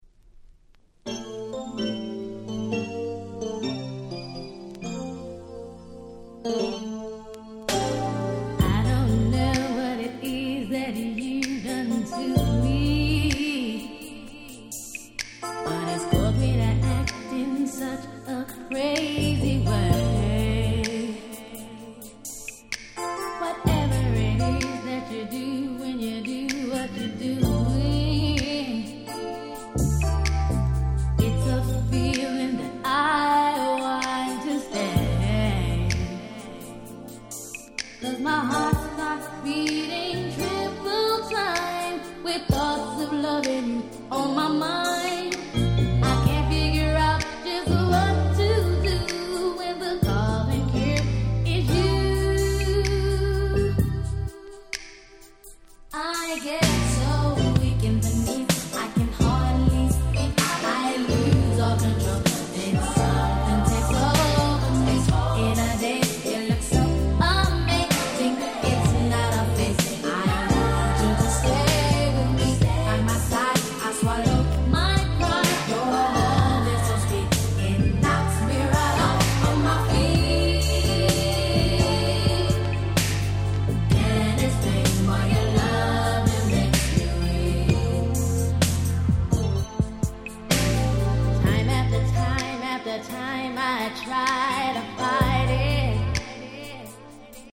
【Media】Vinyl LP
【Condition】B- (薄いスリキズ多め。プチノイズ箇所あり。試聴ファイルでご確認願います。)
92' Big Hit R&B LP !!